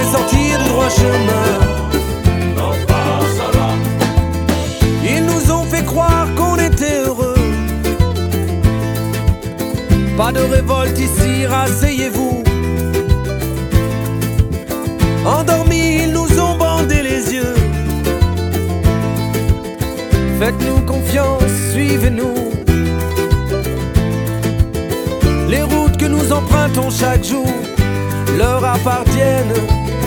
Chansons francophones